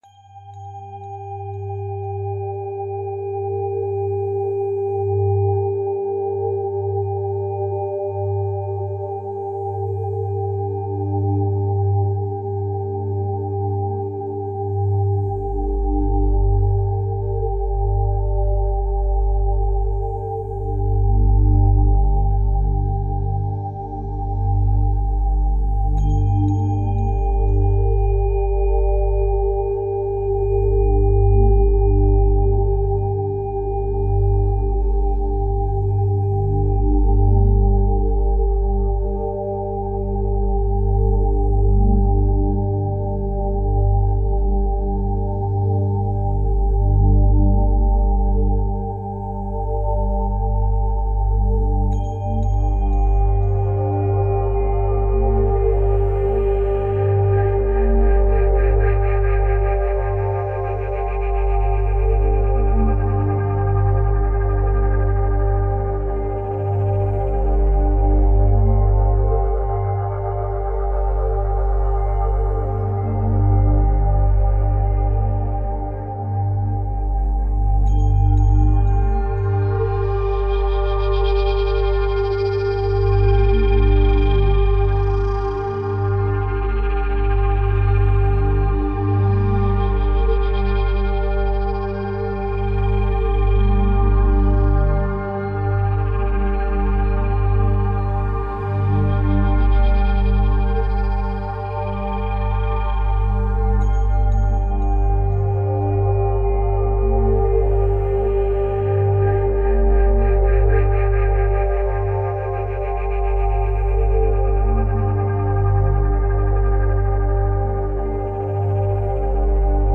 396Hz – فرکانس 396 هرتز
در این فصل می‌خوایم براتون یک سری موسیقی با فرکانس‌های مختلف قرار بدیم که بهشون تون هم می‌گن.
به بعضی از این ها اصطلاحا می‌گن Solfeggio Frequencies که ترجمش فرکانس‌های سلفژی میشه که به فرکانس‌های خاصی اتلاق میشه.
396Hz.mp3